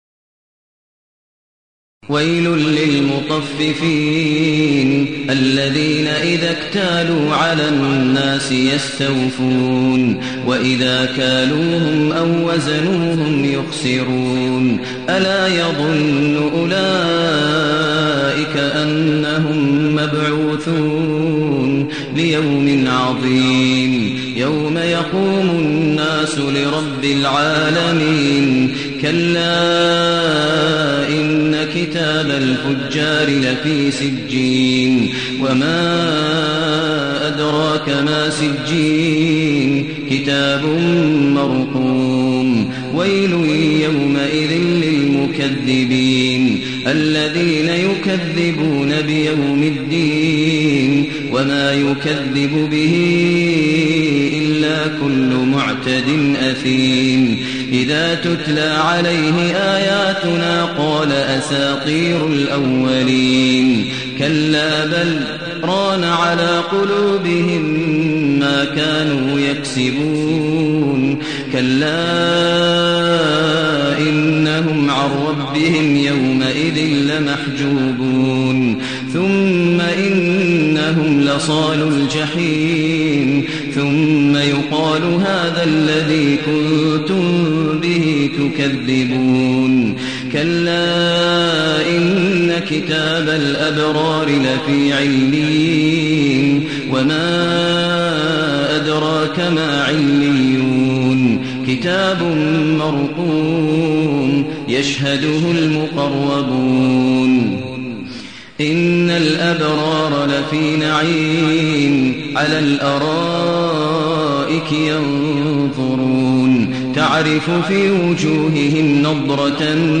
المكان: المسجد الحرام الشيخ: فضيلة الشيخ ماهر المعيقلي فضيلة الشيخ ماهر المعيقلي المطففين